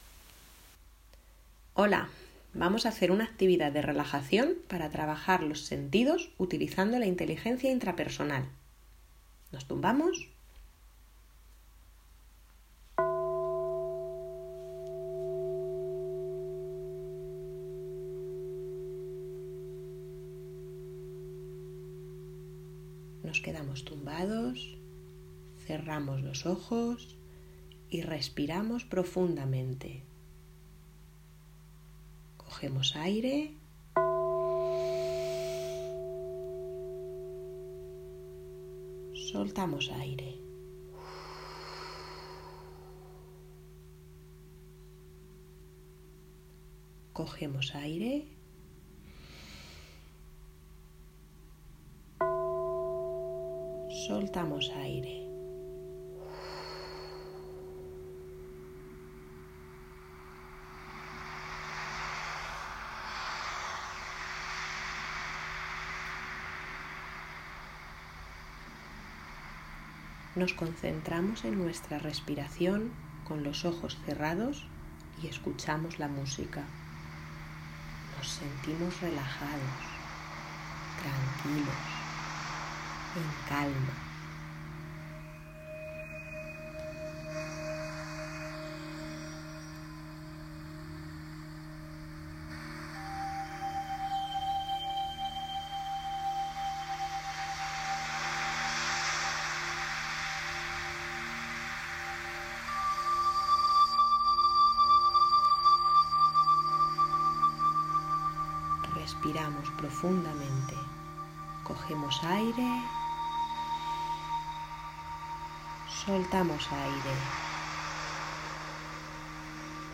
Relajación guiada, respiración profunda, mindfulness, atención plena consciente y ejercicio de visualización con experiencias multisensoriales.